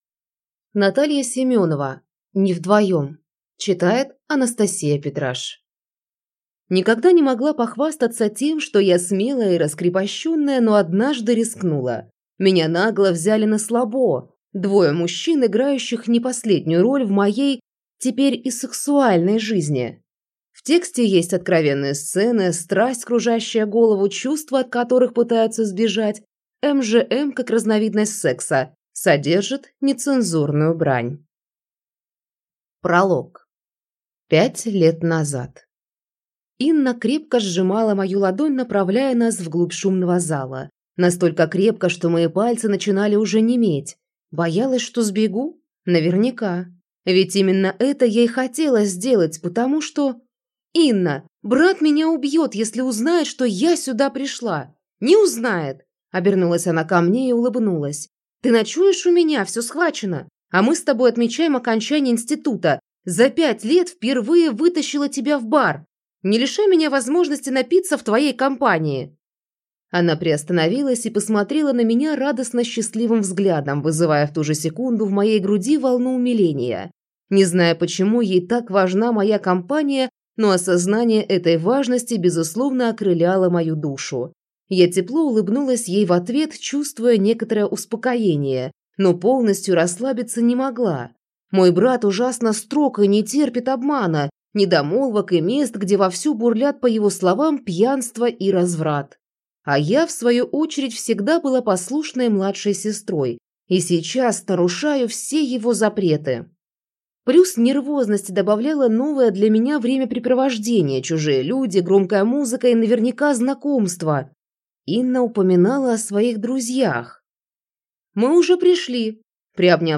Аудиокнига Не вдвоём | Библиотека аудиокниг